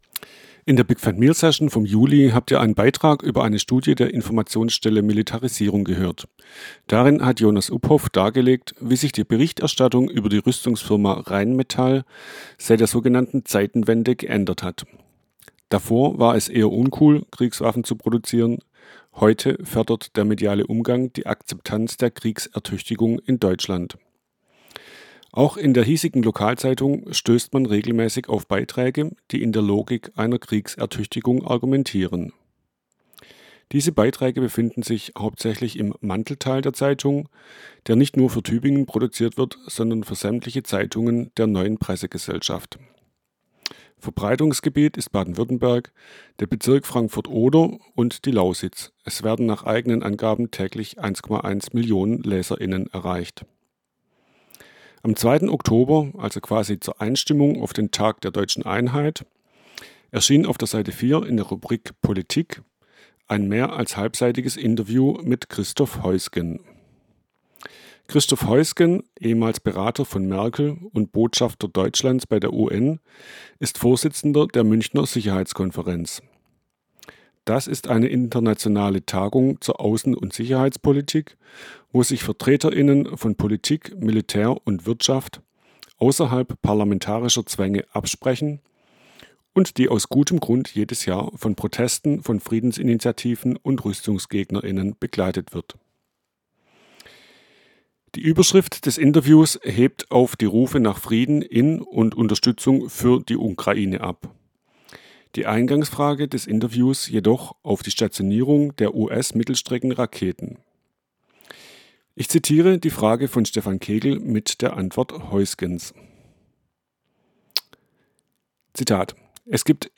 Text-to-Speech-Technologie von Piper und Thorsten-Voice